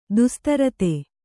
♪ dustarate